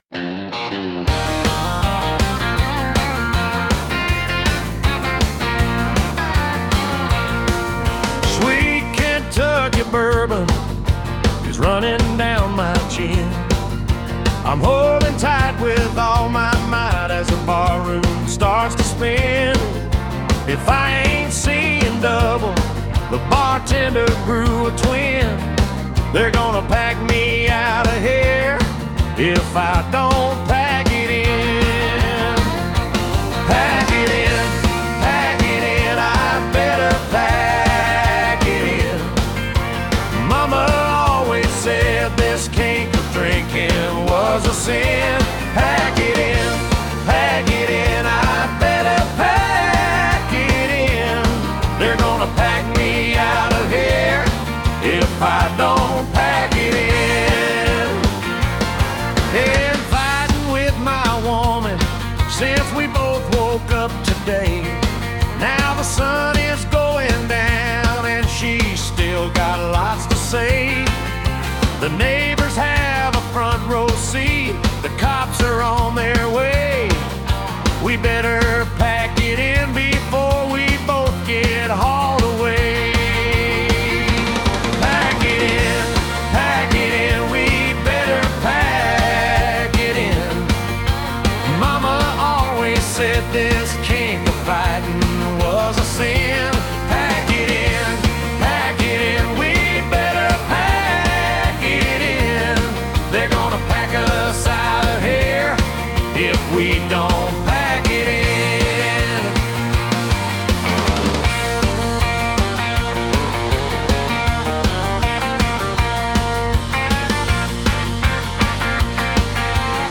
My first AI song using SUNO
We did it to a country style cause the lyrics kinda go with country music.